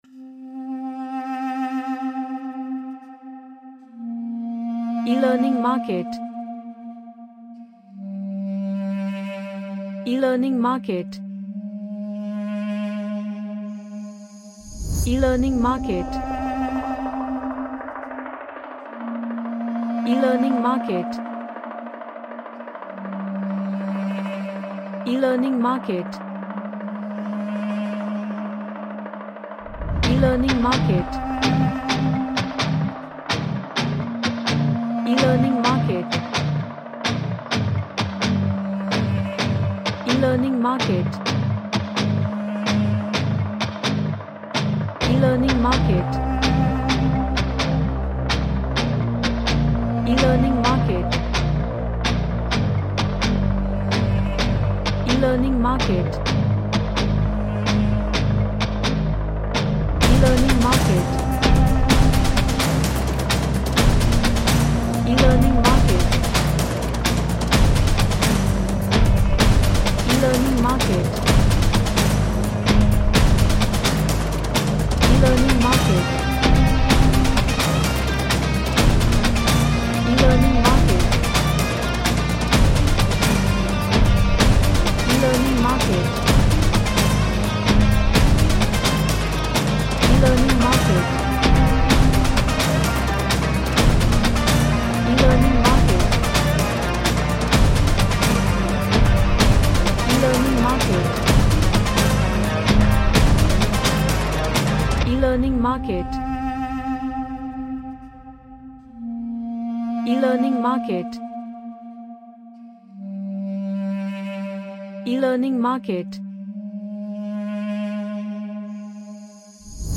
A combination of heavy rock and Cinematic Music
Action / Sports